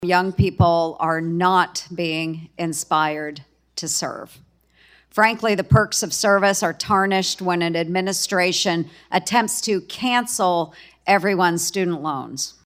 Ernst is a retired National Guard soldier, and made her comments while discussing recruiting with the Secretary of the Navy.